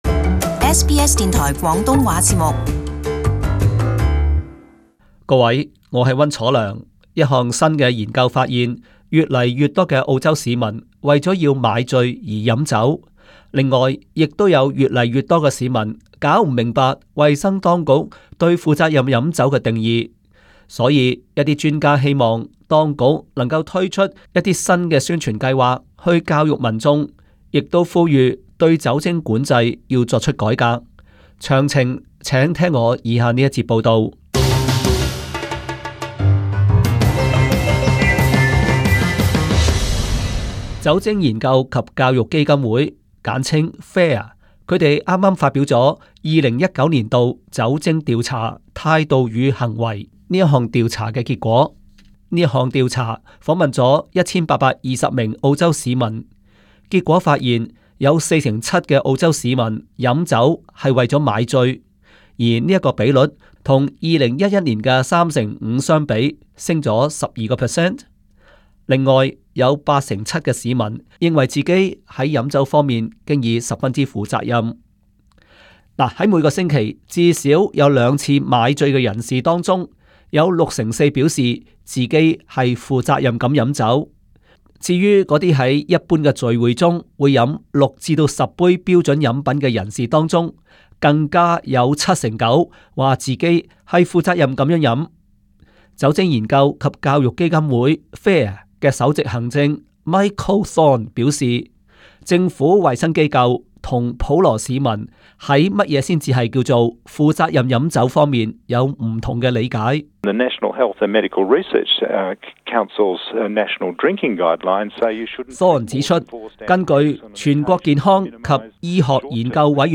Source: AAP SBS廣東話節目 View Podcast Series Follow and Subscribe Apple Podcasts YouTube Spotify Download (11.06MB) Download the SBS Audio app Available on iOS and Android 一項新的研究發現，越來越多澳洲人是為了買醉而飲酒。